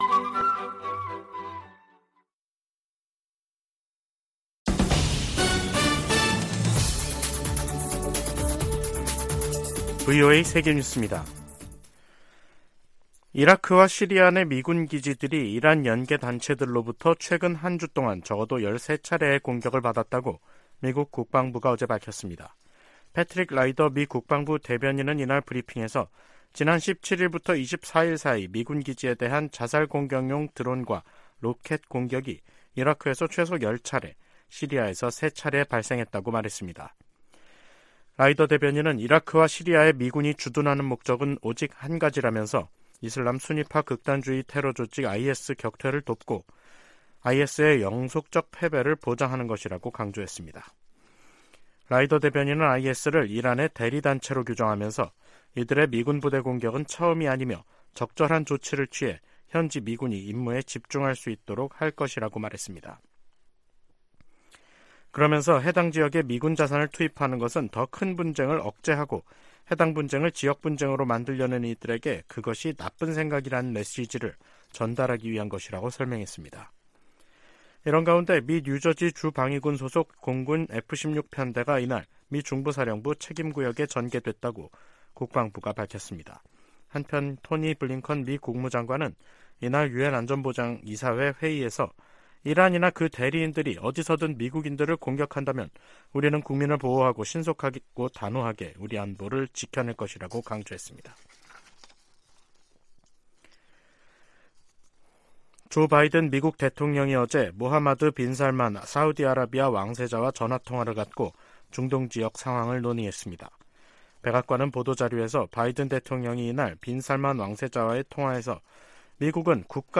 VOA 한국어 간판 뉴스 프로그램 '뉴스 투데이', 2023년 10월 25일 2부 방송입니다. 유럽연합(EU)이 탈북민 강제북송 문제가 포함된 북한인권 결의안을 유엔총회에 제출할 계획입니다. 한국은 유엔총회에서 북한과 러시아에 불법 무기거래를 즉각 중단라고 촉구했습니다.